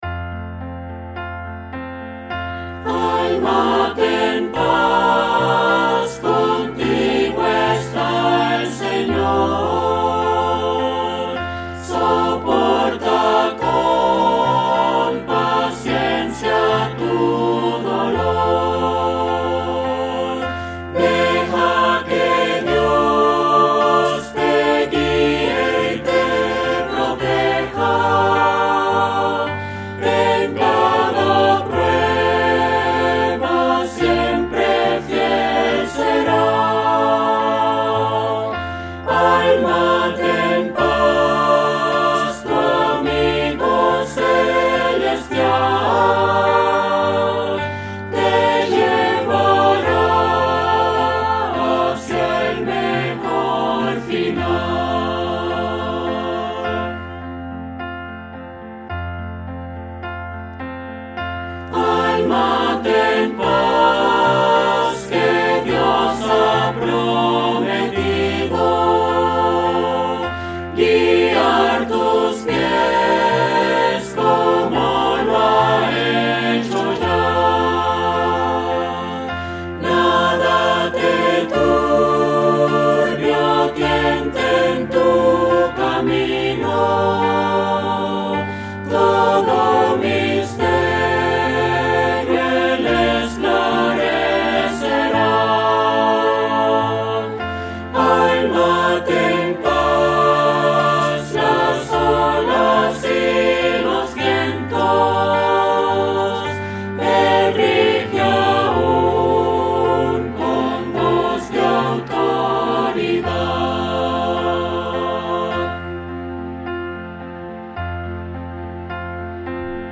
Cantado (Descargar audio)